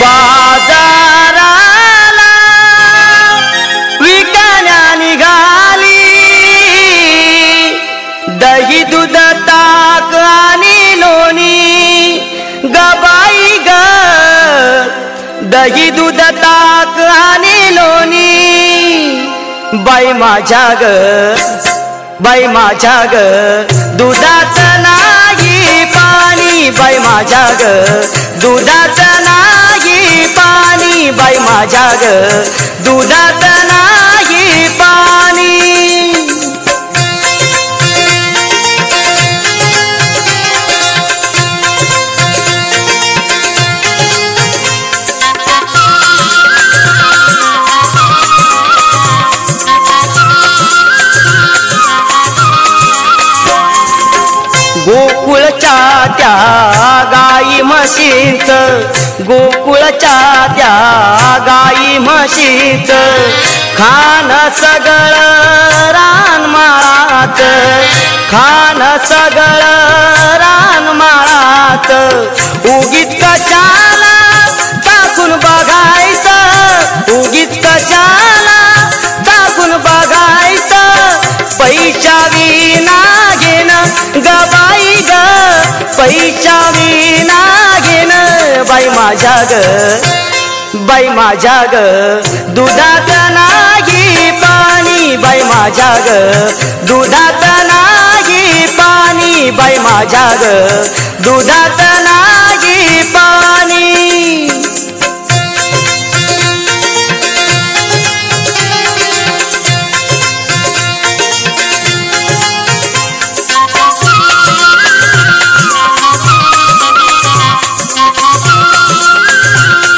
• Category:Marathi Single